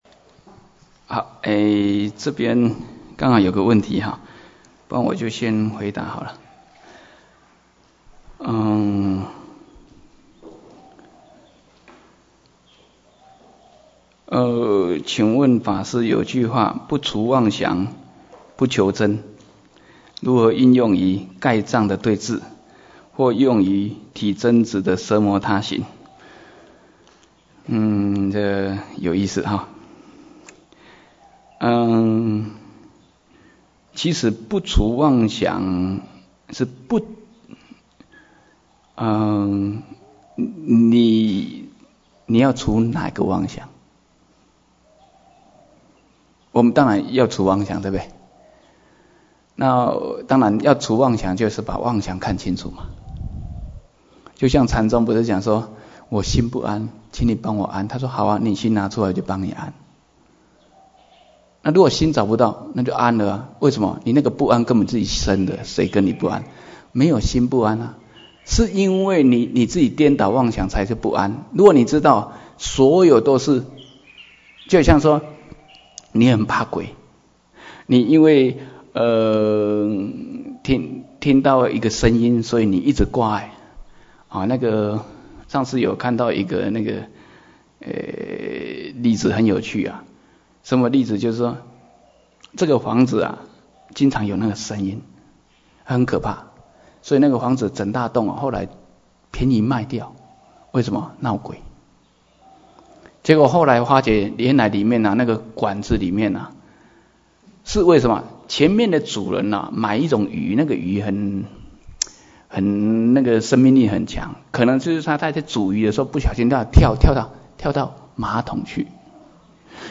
瑜伽师地论摄抉择分016(問答).mp3